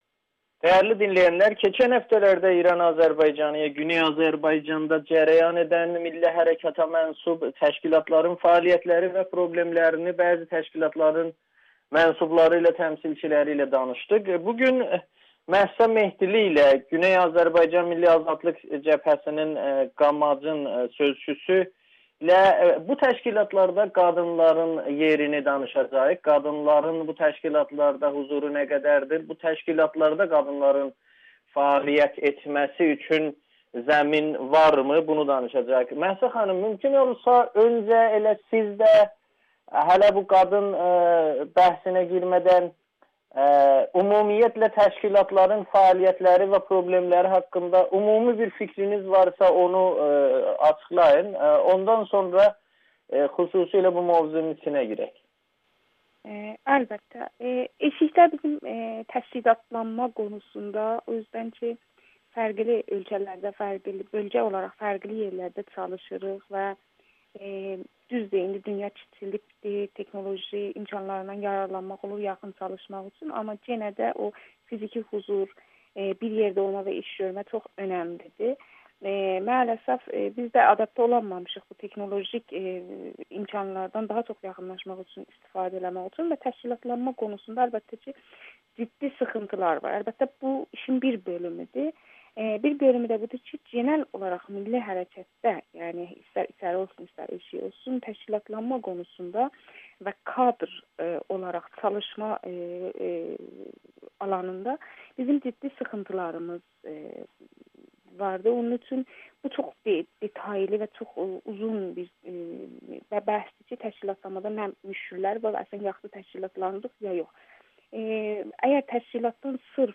milli hərəkatda qadın qalmayacaq [Audio-Müsahibə]